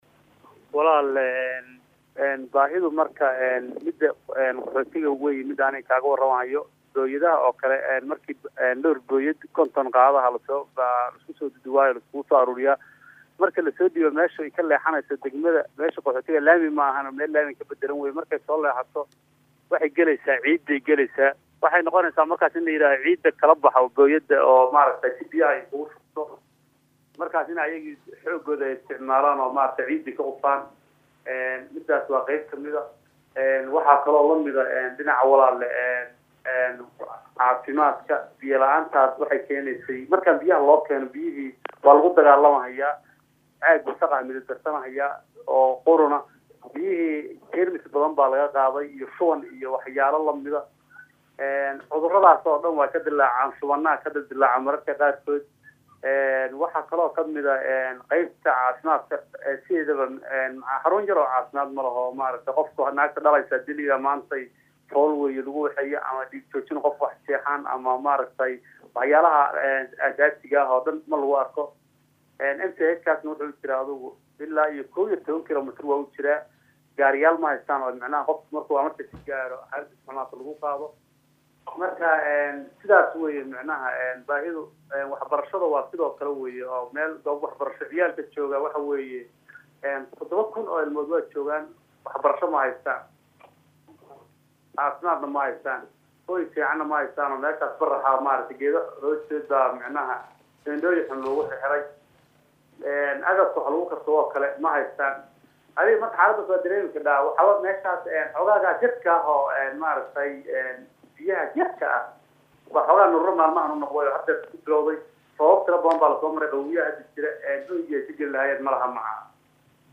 WARAYSI-GALINSOOR.mp3